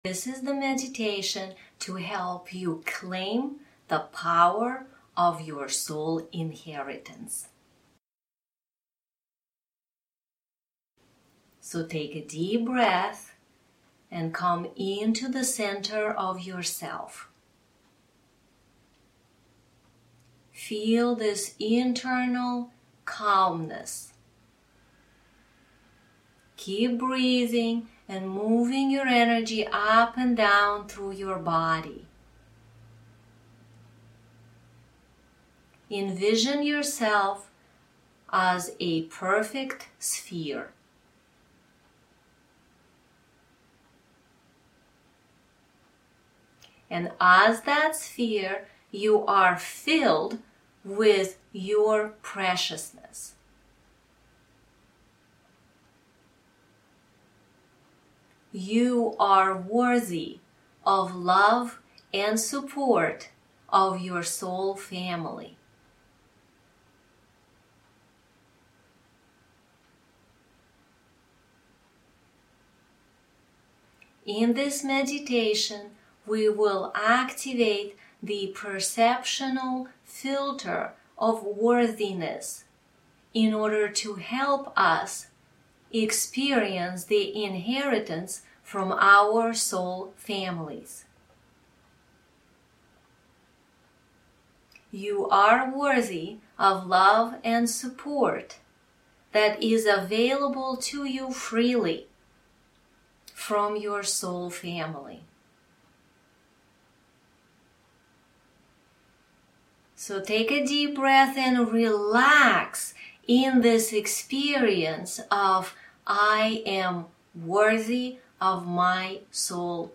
meditation 1